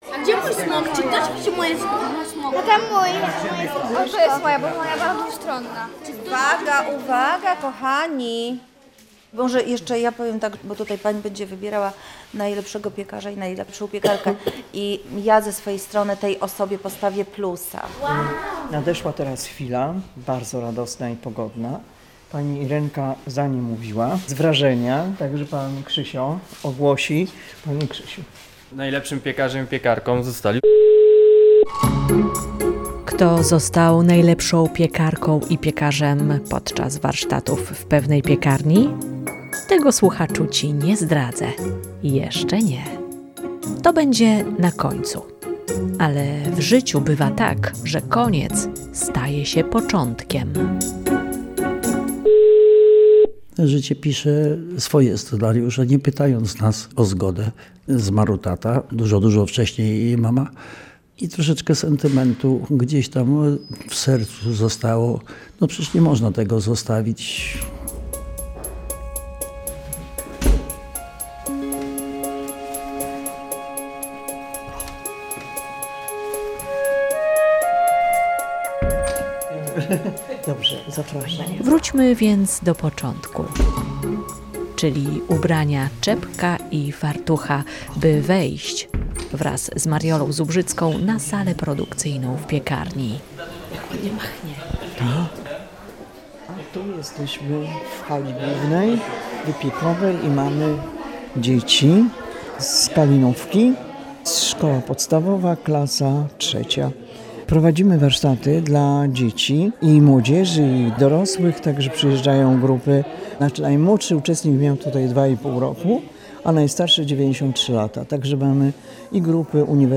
To będzie reportaż o tradycjach piekarniczych i trudnych, ale odważnych decyzjach.